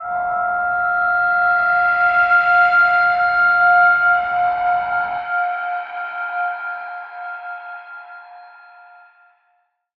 G_Crystal-F6-f.wav